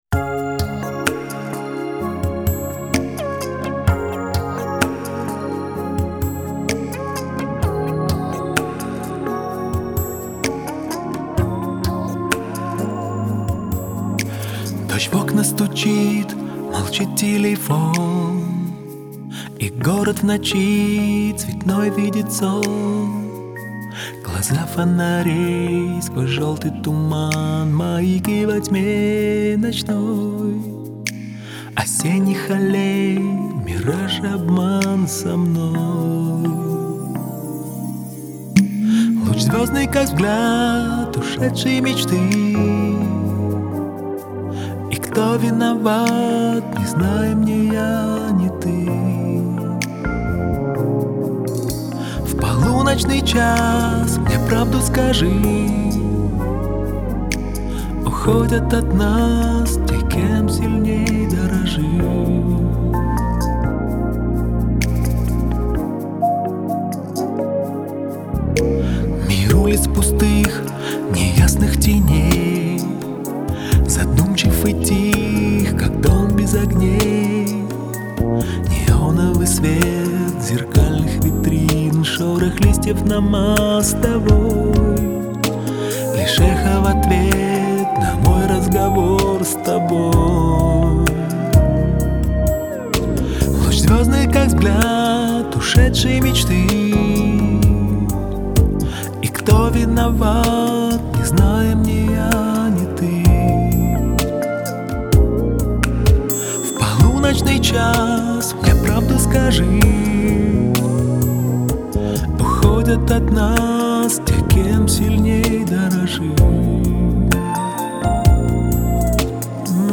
это трогательная песня в жанре поп с элементами фолка